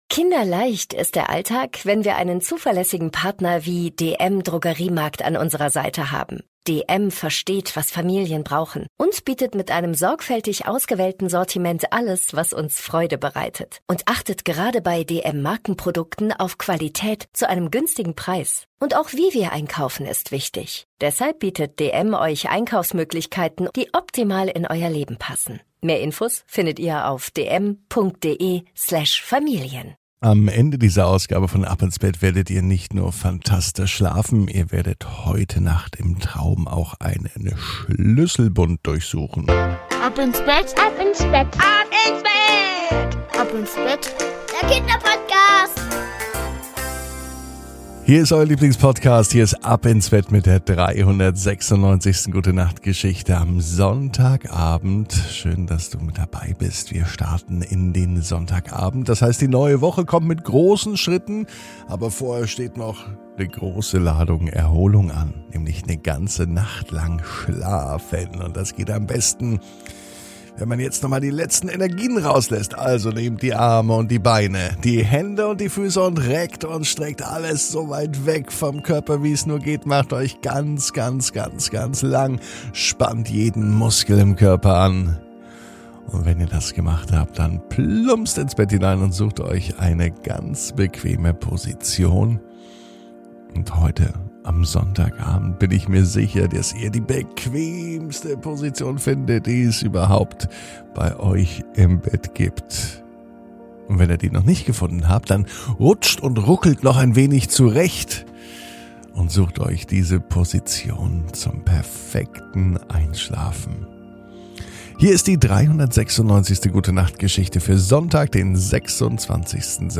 #396 Sören und der Schlüssel aus der Schüssel ~ Ab ins Bett - Die tägliche Gute-Nacht-Geschichte Podcast